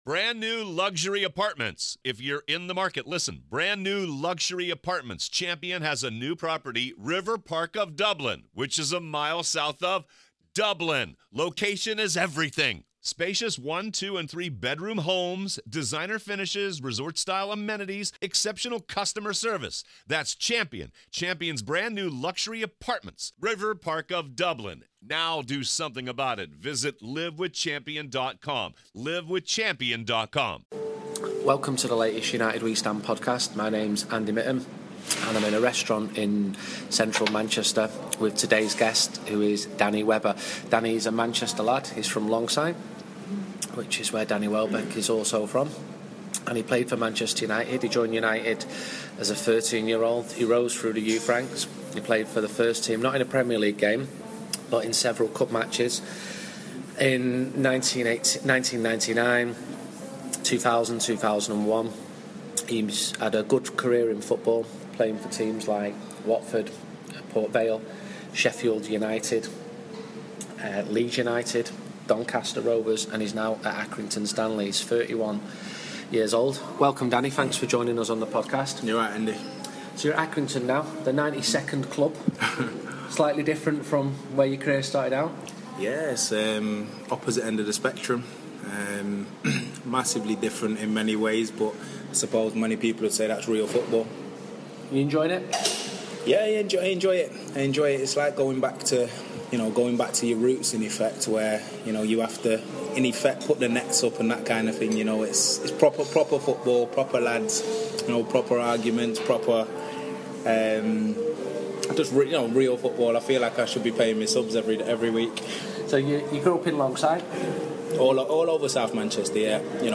in a Manchester bar